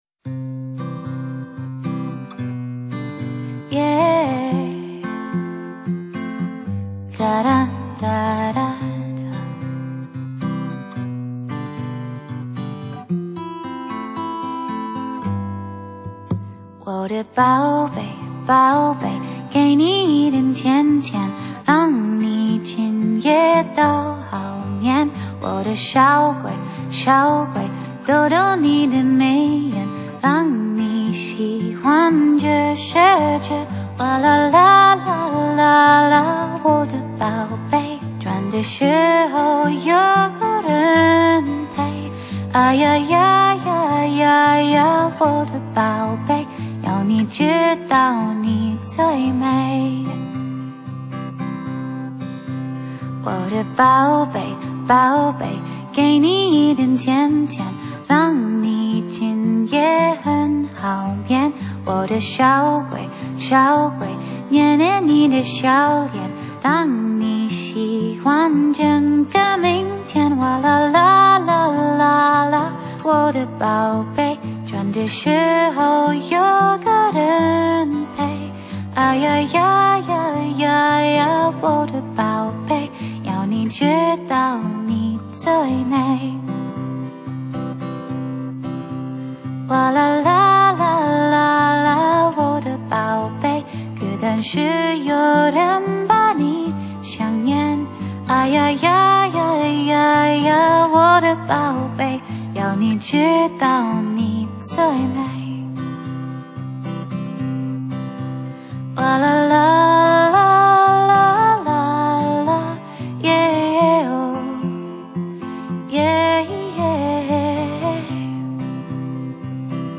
性感女高音
溫柔典雅
平靜和舒緩的曲目, 與吉他, 鋼琴, 長笛於溫暖的音符中, 創造一個和平與安寧的氣氛世界.